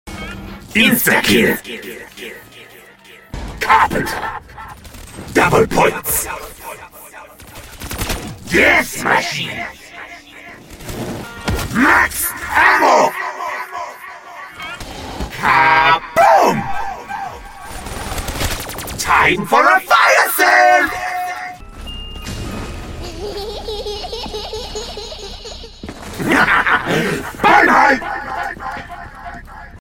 Power Ups Announced by Richtofen sound effects free download
Power Ups Announced by Richtofen in Moon